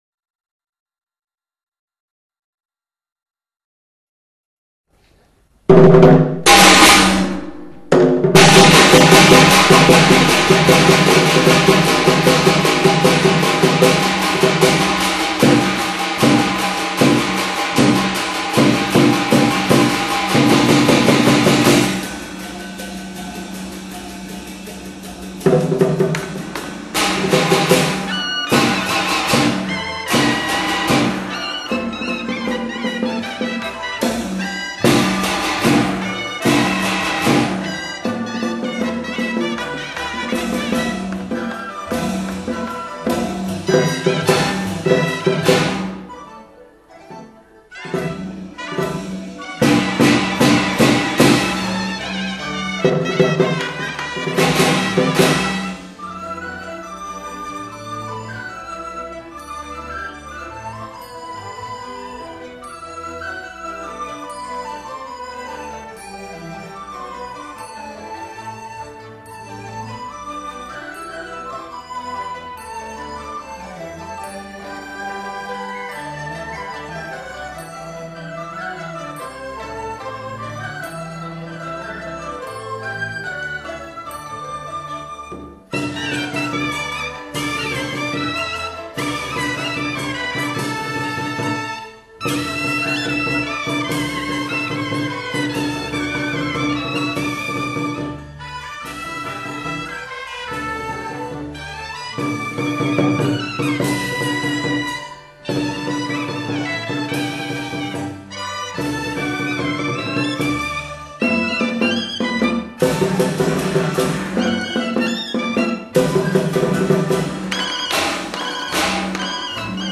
[16/9/2009]【热烈祝贺社区新域名启航】《潮州大锣鼓》 激动社区，陪你一起慢慢变老！